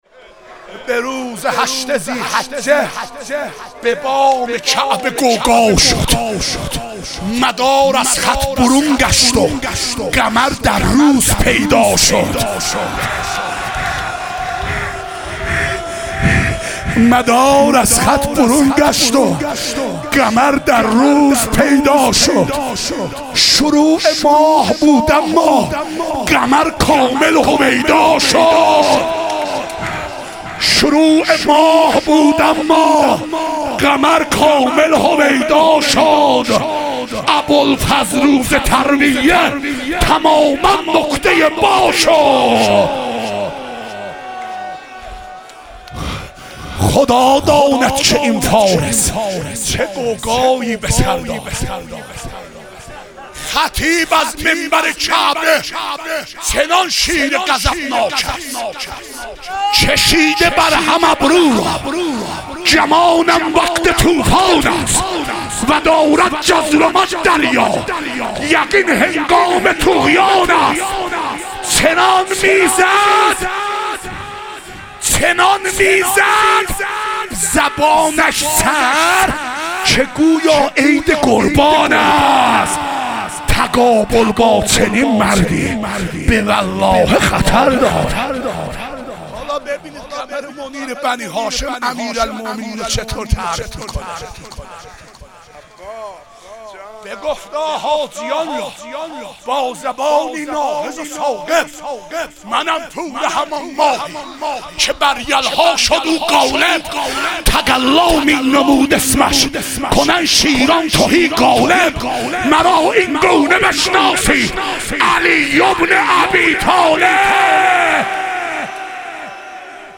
عید سعید غدیر خم - مدح و رجز